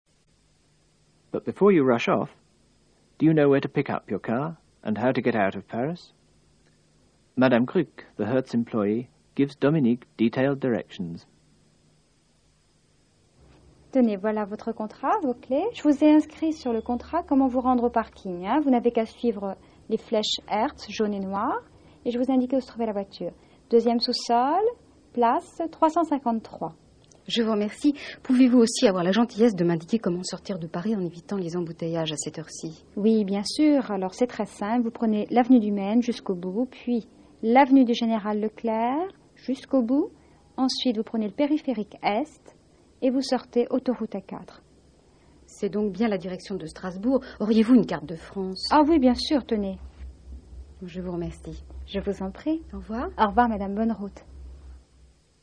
Both are recordings of people seeking directions.